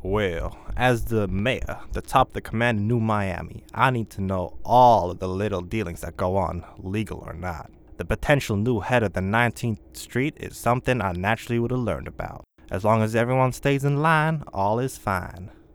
Voice Lines
Update Mayor Dialogue Tree for Voice Overs